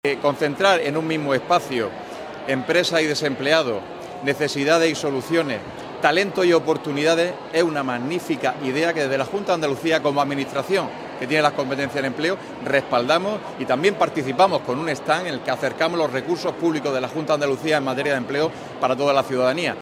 AMOS-GARCIA-DELEGADO-DE-EMPLEO-EMPRESA-Y-TRABAJO-AUTONOMO-JUNTA.mp3